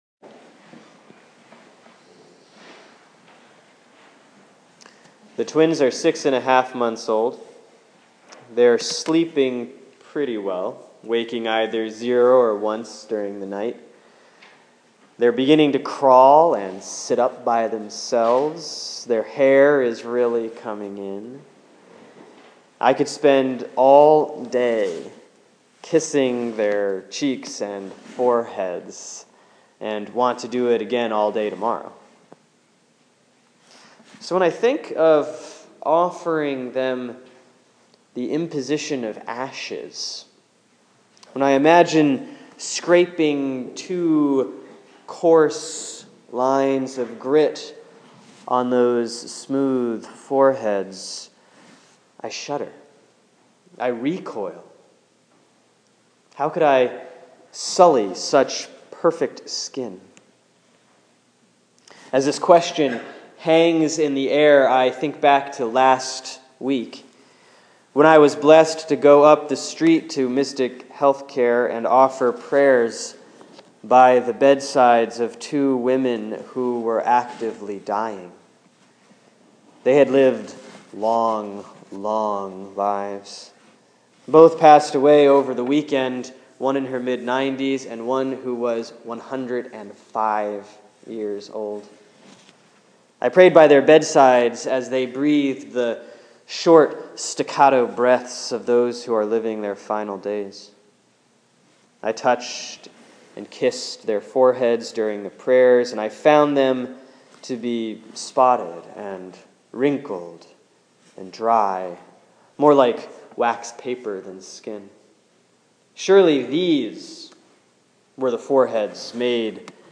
Sermon for Ash Wednesday, February 18, 2015 || Isaiah 58:1-12; Psalm 103